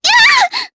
Rodina-Vox_Damage_jp_01.wav